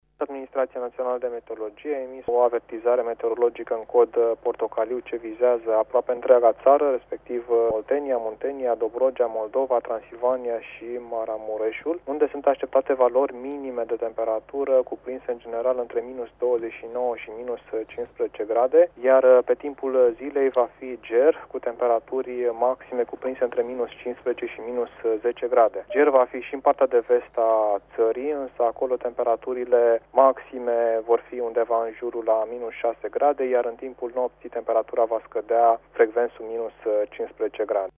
precizează meteorologul de serviciu